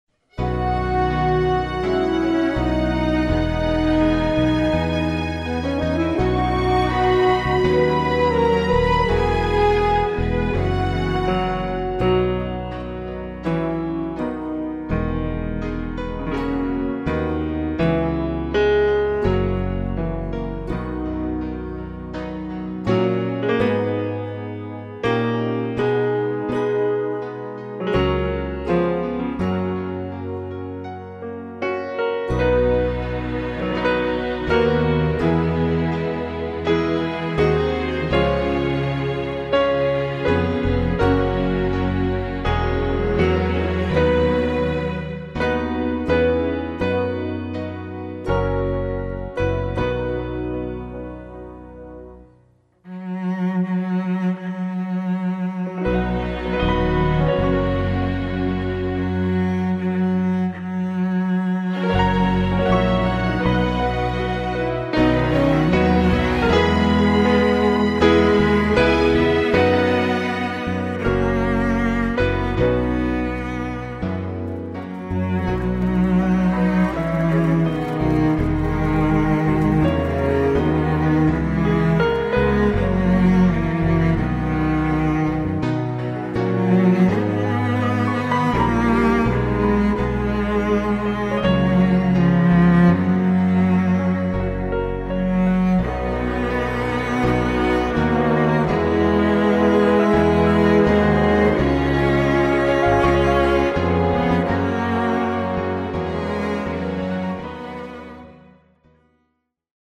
Original Key with Background Vocals